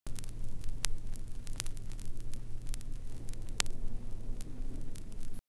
VINYL2    -L.wav